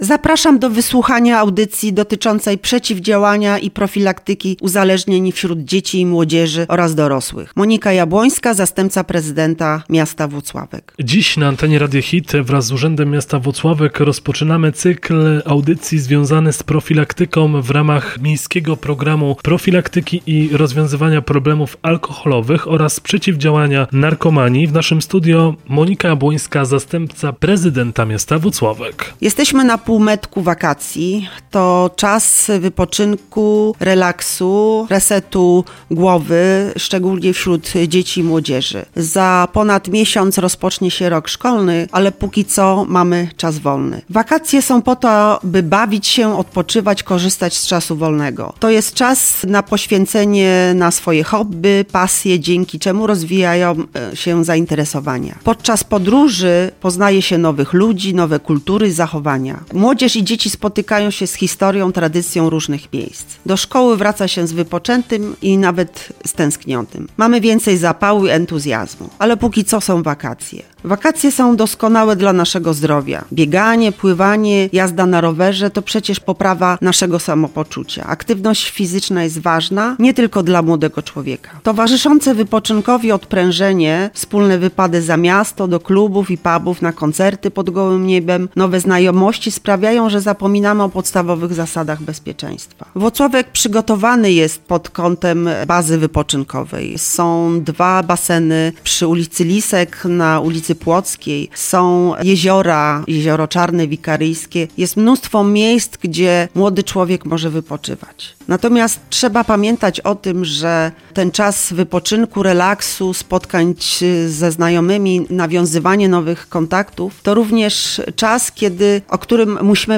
Naszym pierwszym Gościem Była Zastępca Prezydenta Miasta Włocławek Monika Jabłońska